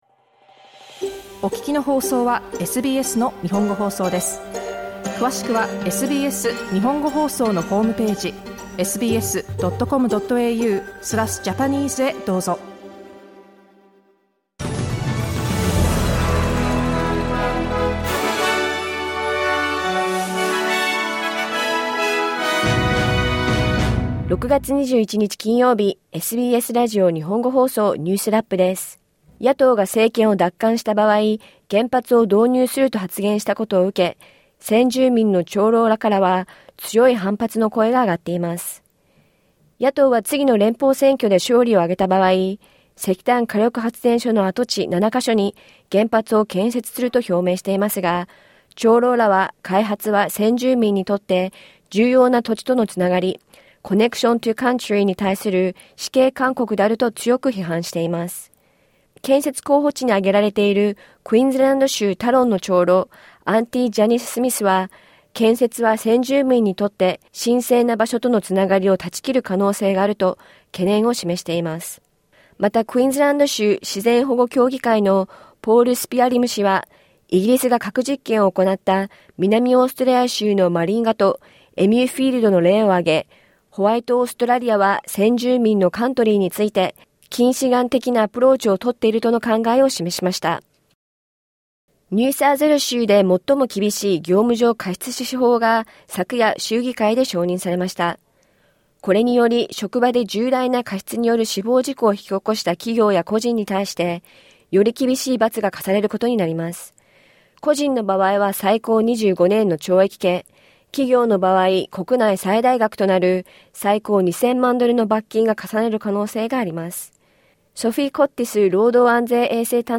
SBS日本語放送週間ニュースラップ 6月21日金曜日